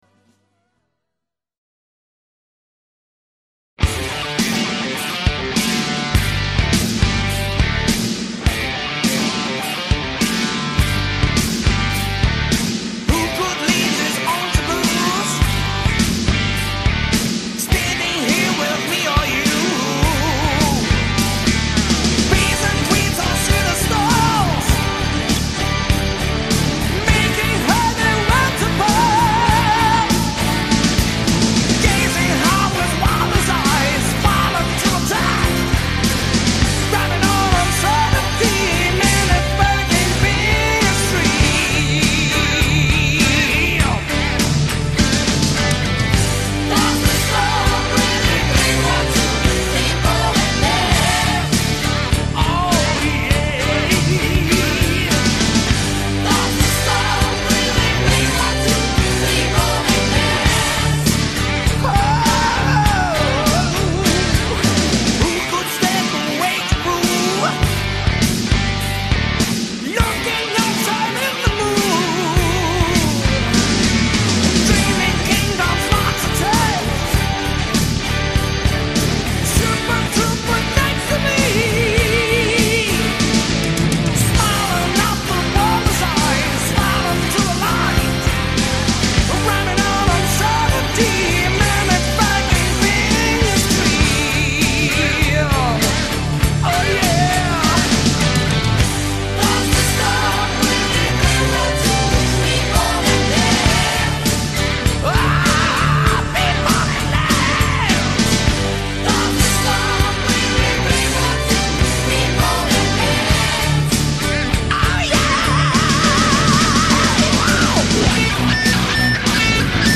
chant
guitare
basse
batterie
claviers
choeurs